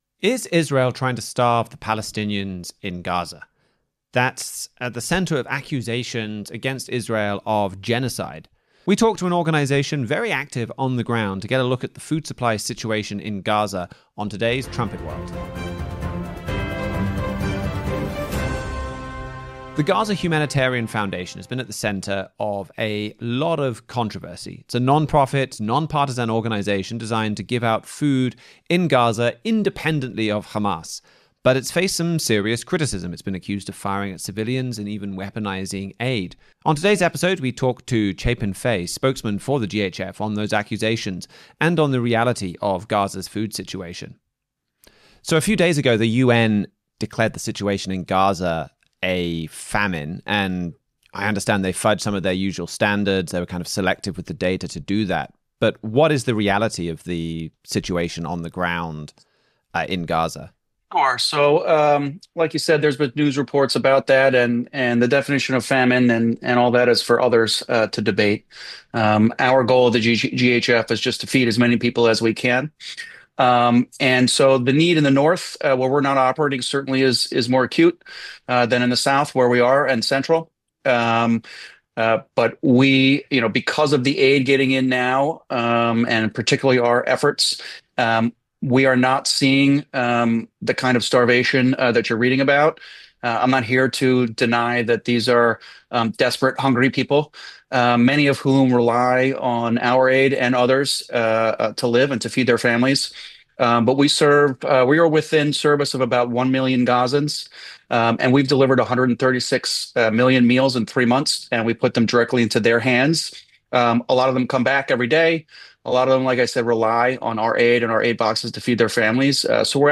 trumpet-world-112-interview-with-the-gaza-humanitarian-foundation.mp3